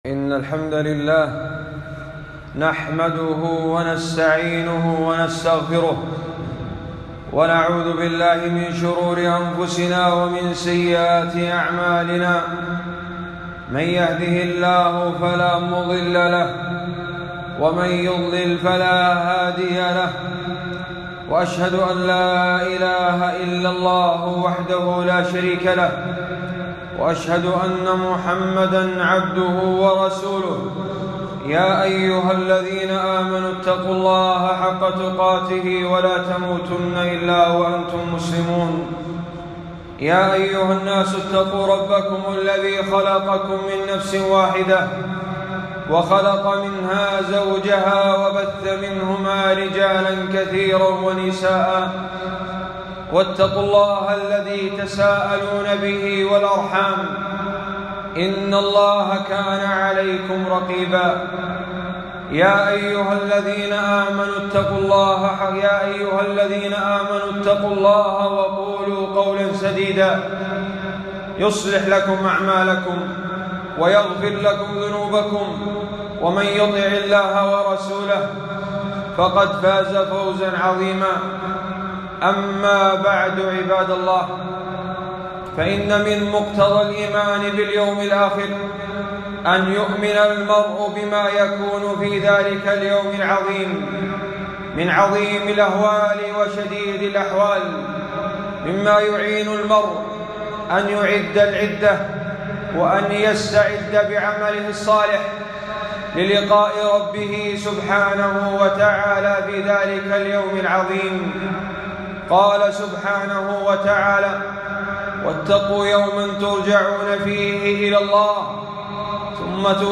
خطبة - (وجاء ربك والملك صفا صفا )